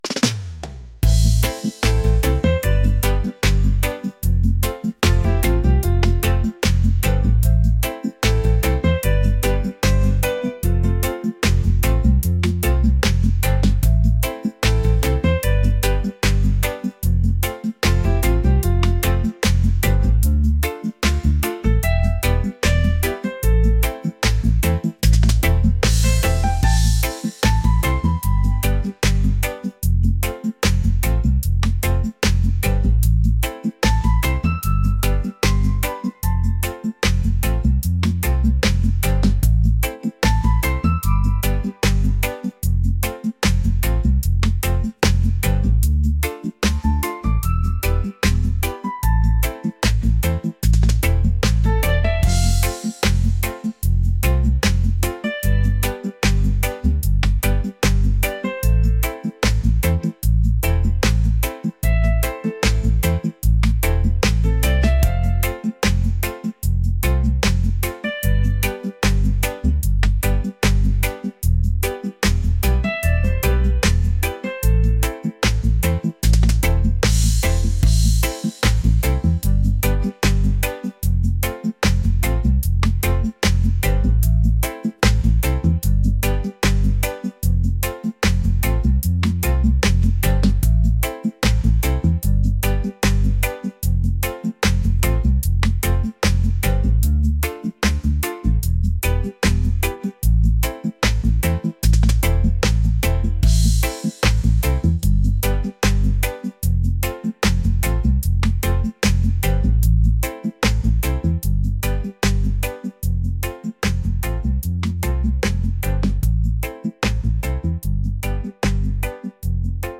laid-back | reggae | romantic